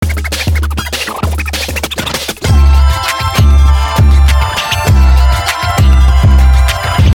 i just sped it up